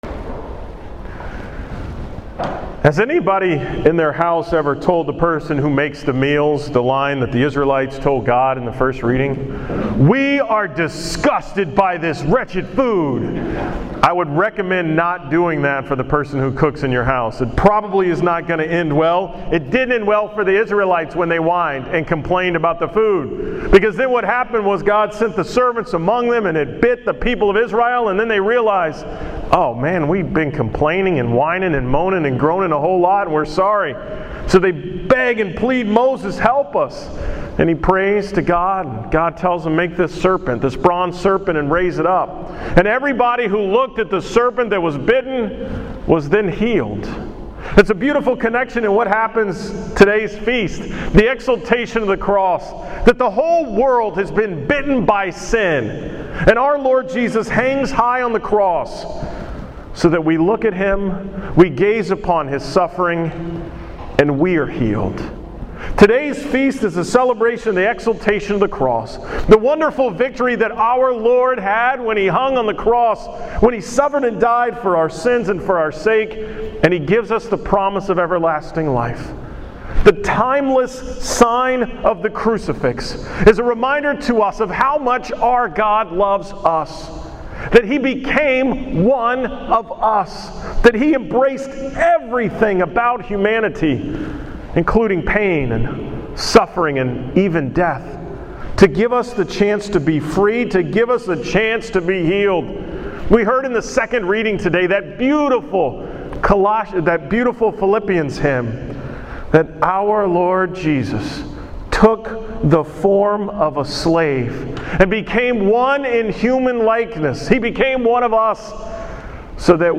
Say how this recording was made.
From the 9 am Mass at St. Albert on September 14, 2014